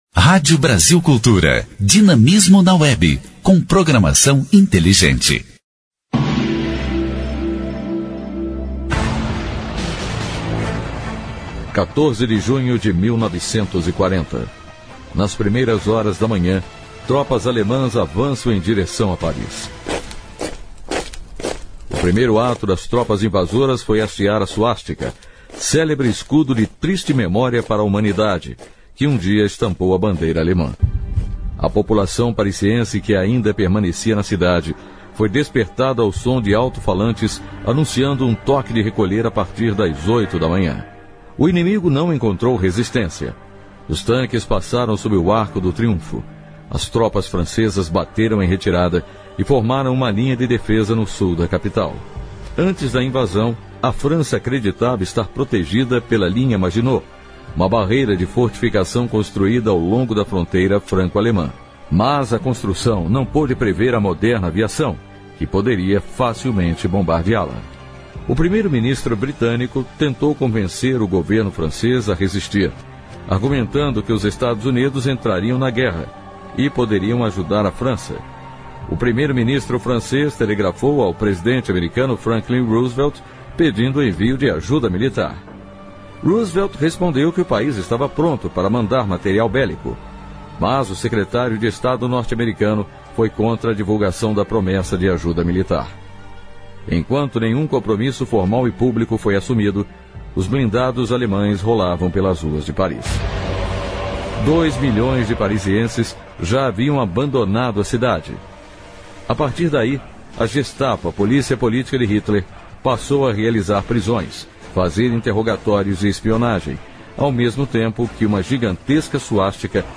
Programete sobre fatos históricos relacionados às datas do calendário.